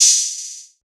TM88 - OPEN HAT (1).wav